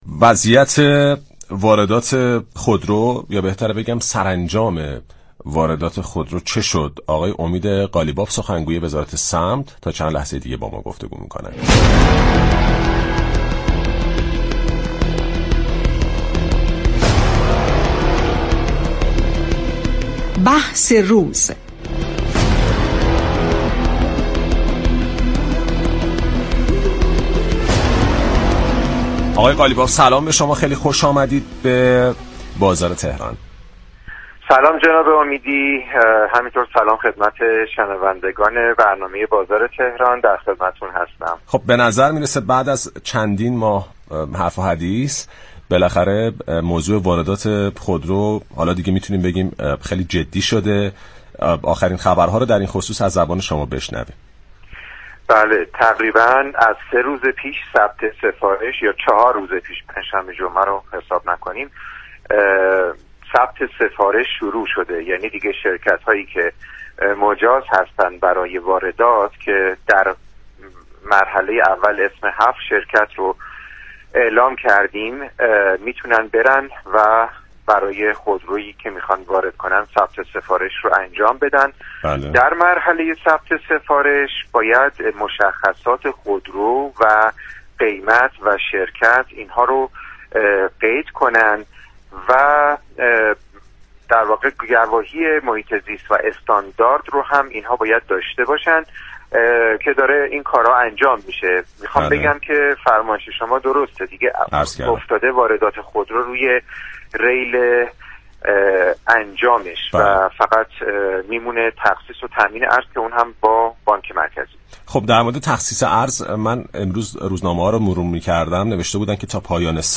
در گفت و گو با "بازار تهران" رادیو تهران